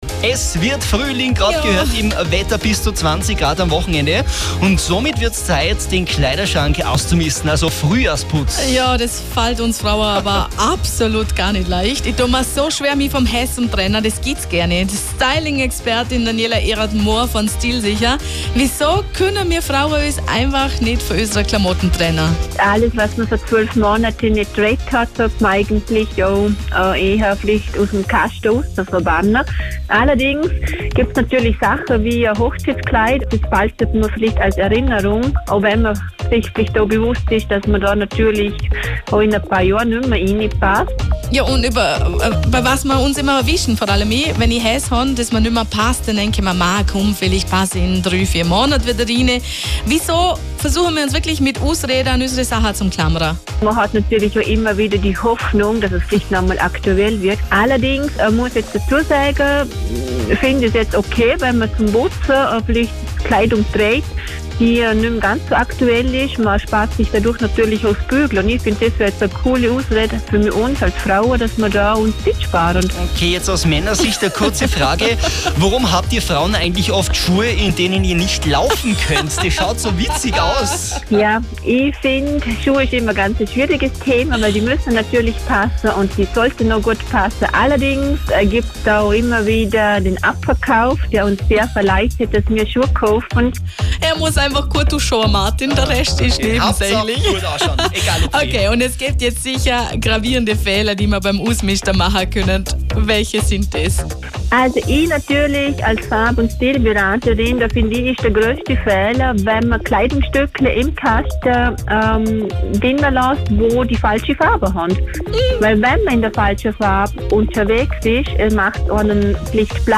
Frühjahrsputz im Kleiderschrank Interview auf Antenne Vorarlberg, März 2019